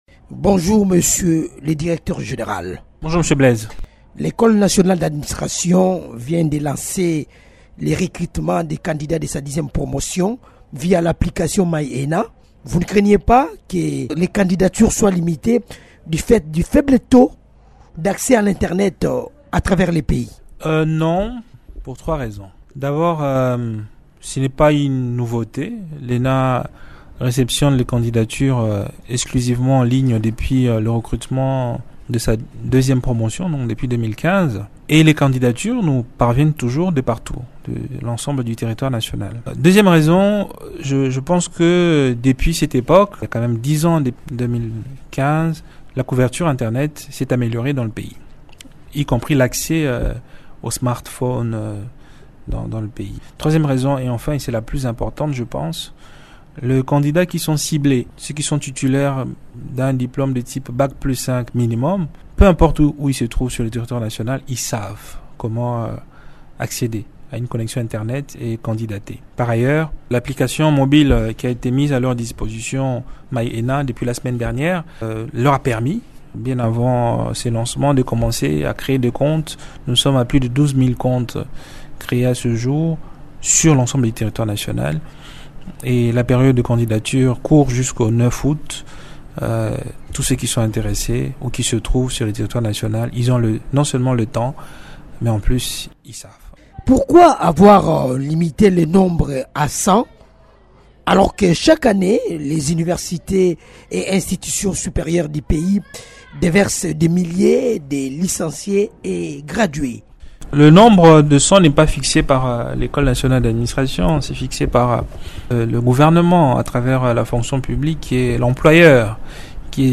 L’École nationale d’administration (ENA) recrute, du 31 juillet au 9 août 2025, les candidats de sa 10ᵉ promotion. Invité de Radio Okapi, le Directeur général de cet établissement public, Tombola Muke, annonce que 100 candidats seront retenus pour une formation intensive de 12 mois.